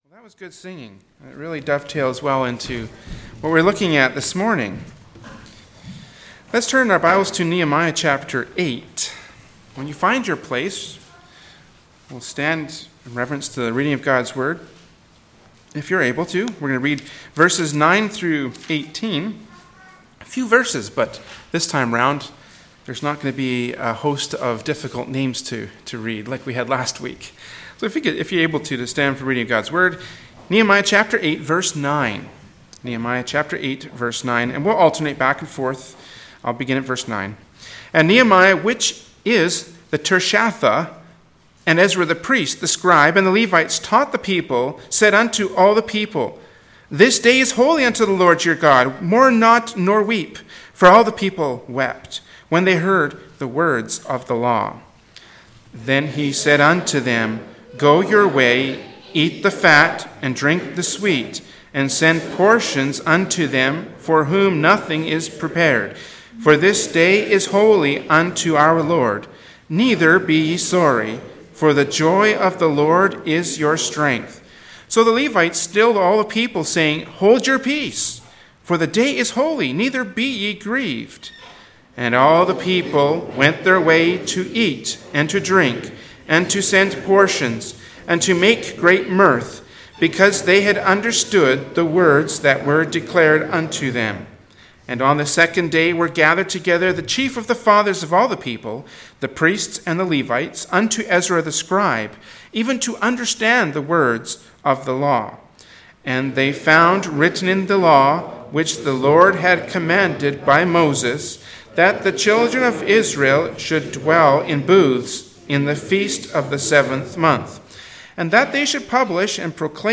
“Great Gladness” from Sunday Morning Worship Service by Berean Baptist Church.